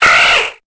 Cri de Zigzaton dans Pokémon Épée et Bouclier.